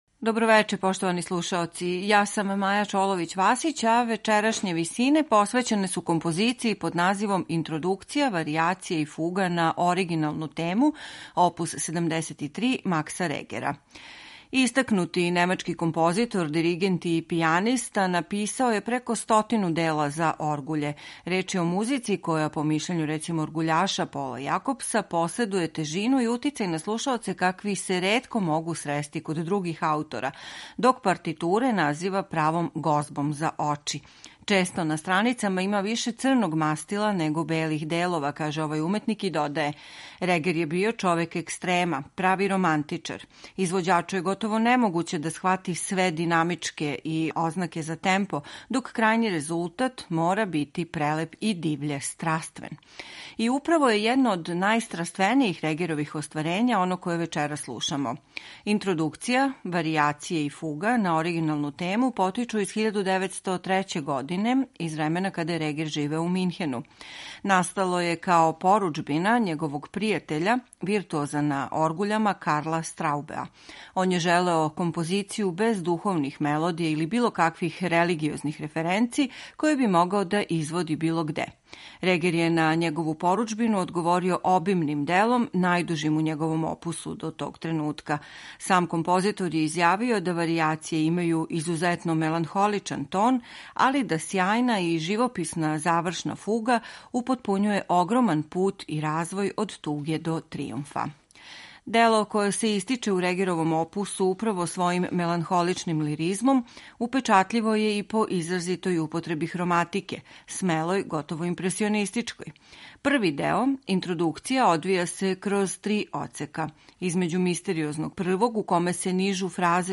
Једну од најдужих и најстраственијих оргуљских композиција немачког романтичара, са истакнутом употребом хроматике